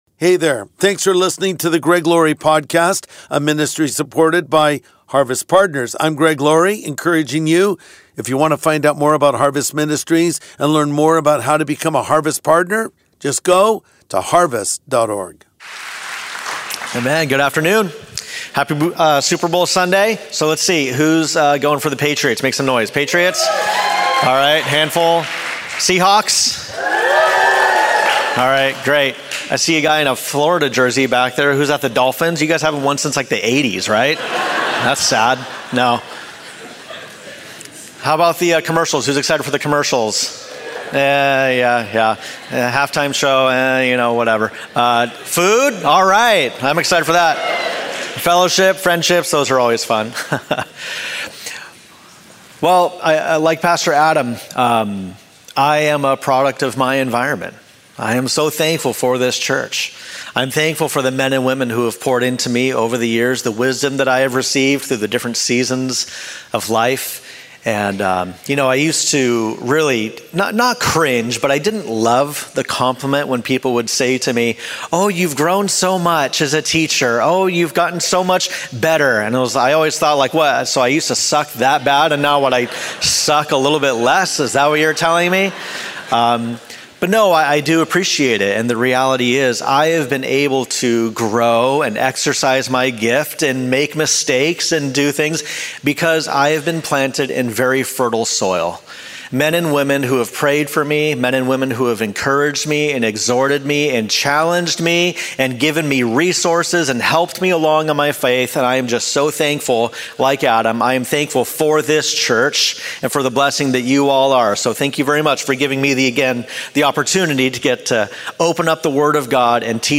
Lines in the Sand | Sunday Message